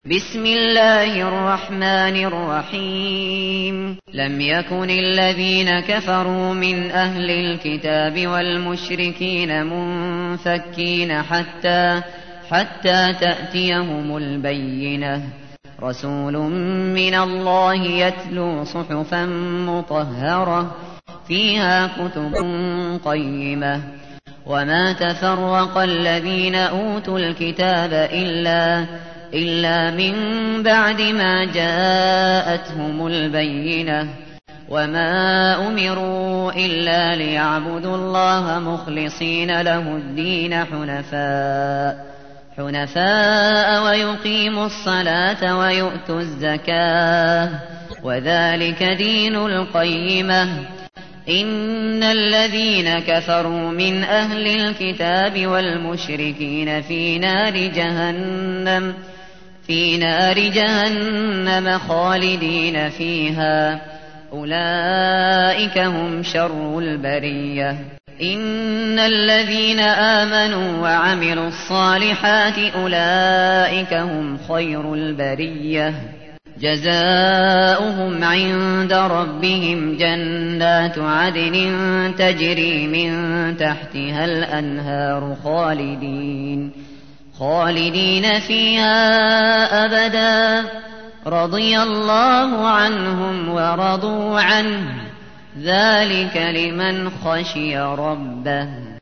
تحميل : 98. سورة البينة / القارئ الشاطري / القرآن الكريم / موقع يا حسين